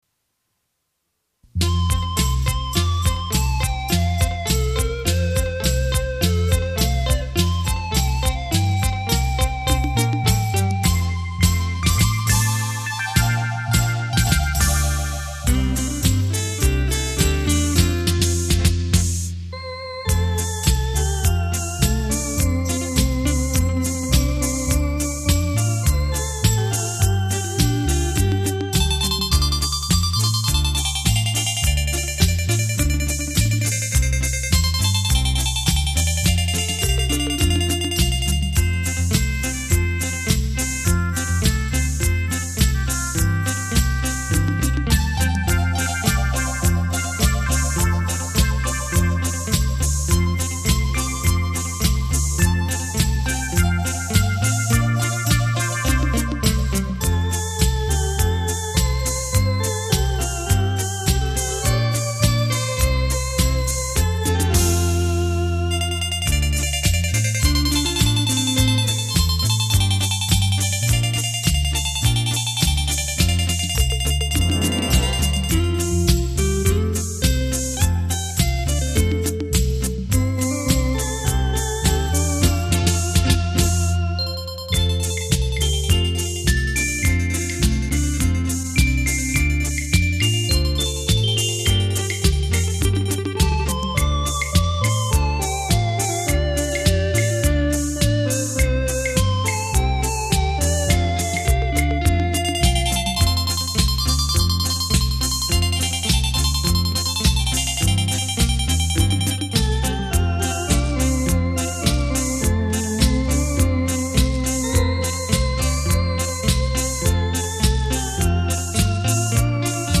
【專輯類型：演奏音樂】